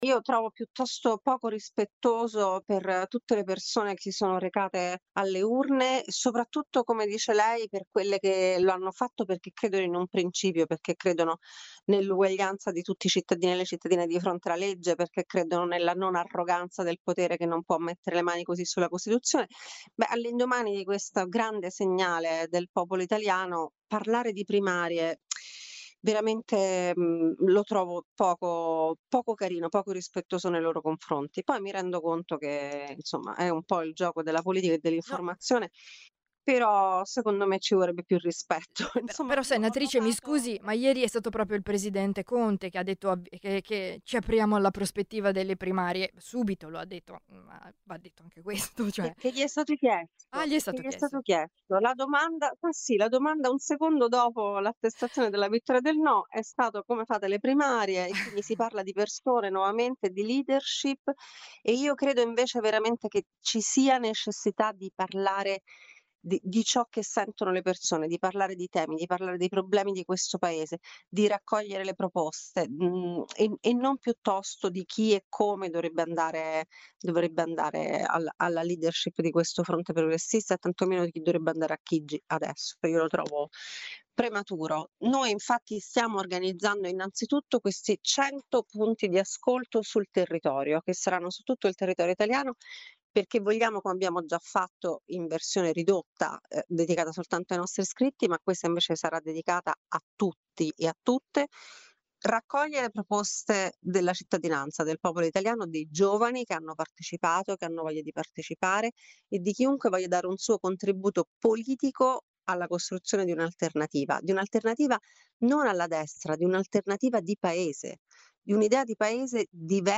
Risponde Alessandra Maiorino, Senatrice del Movimento CinqueStelle: “Noi stiamo proponendo 100 punti di ascolto per raccogliere le proposte di chiunque voglia dare il suo contributo politico a una idea di Paese diversa da quella rappresentata oggi.